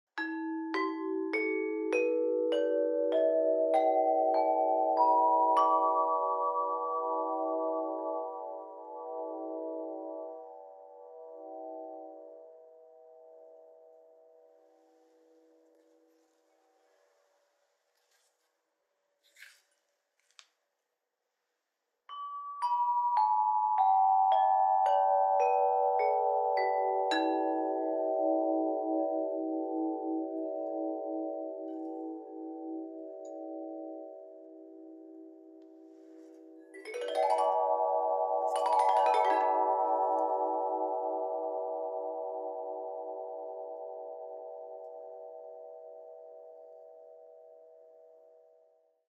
Meinl Sonic Energy Meditation Flow Chime 38"/95 cm - 432 Hz/10 Notes/E Minor - Black (MFC10EMIBK)
Meinl Sonic Energy Meditation Flow Chimes are high-quality instruments that are perfect for meditation, relaxation, and sound therapy.